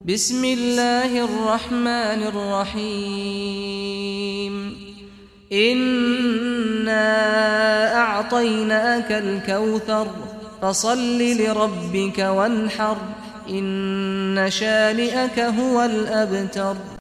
Surah Kausar Recitation by Sheikh Saad al Ghamdi
Surah Kausar, listen or play online mp3 tilawat / recitation in Arabic in the beautiful voice of Sheikh Saad al Ghamdi.